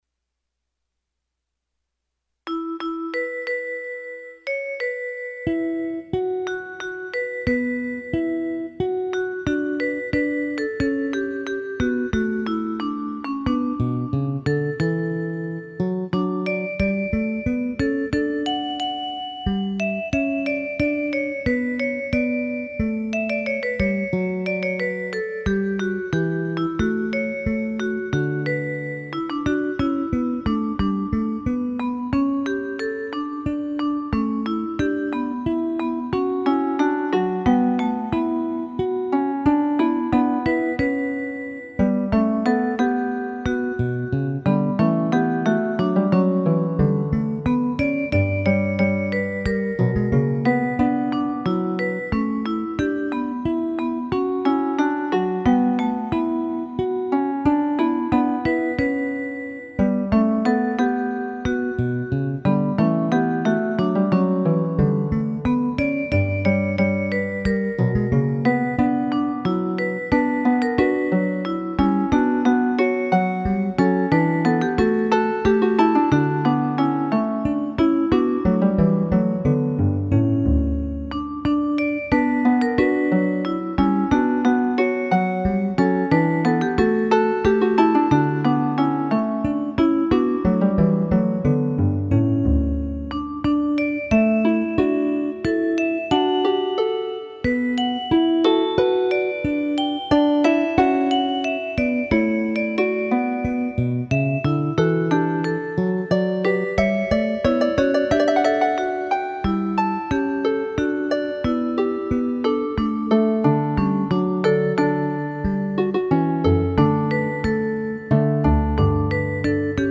CHANSON ; POLYPHONIC MUSIC